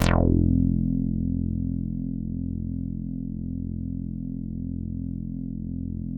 HARD CUTOFF1.wav